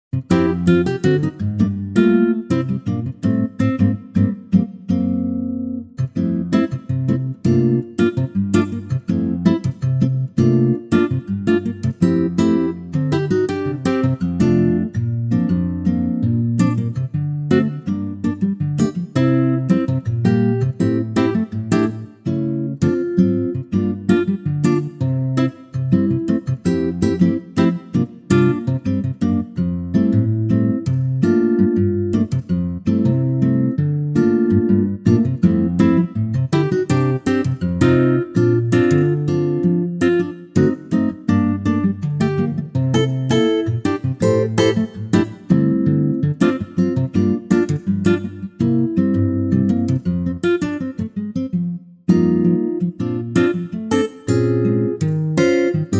Unique Backing Tracks
4 bar intro and vocal in at 4 seconds. vocal through
key - F - vocal range - F to Ab (optional A)
Superb acoustic guitar arrangement